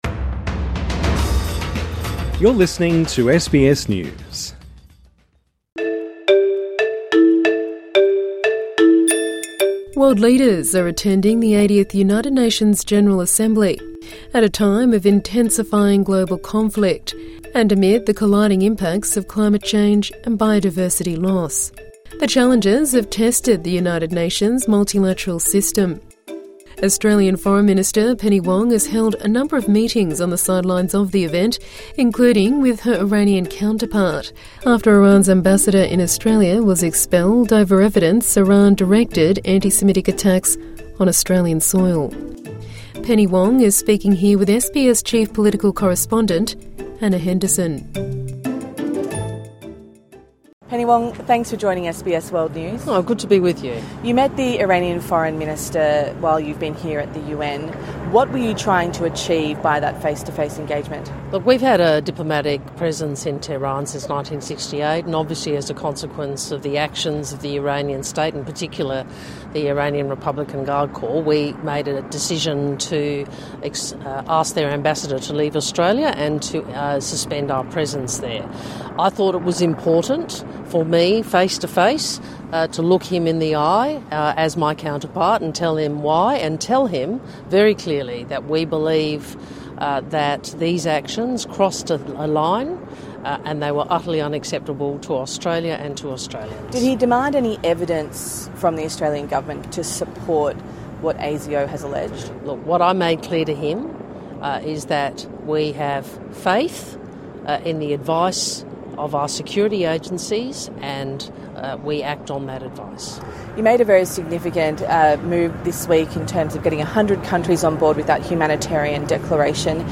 INTERVIEW: Penny Wong on the upcoming Albanese-Trump meeting and Australia's bid to host COP31